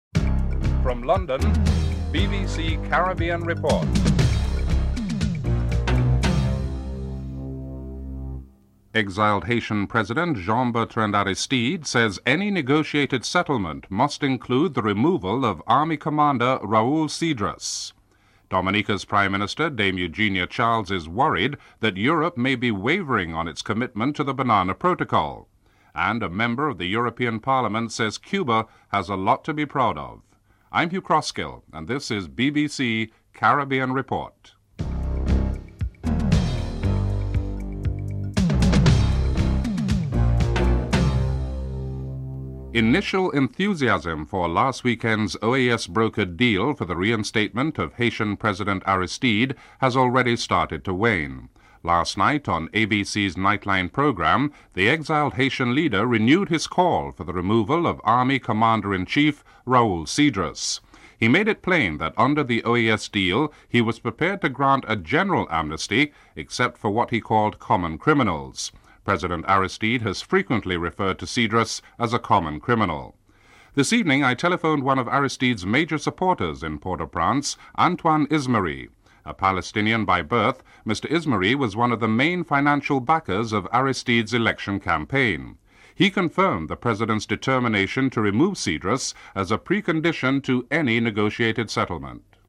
1. Headlines (00:00-00:41)
4. An interview with Dominica’s president Dame Eugenia Charles reveals that she remains unconvinced that the European community will honour its commitment to Caribbean bananas after 1992 (05:06-07:55)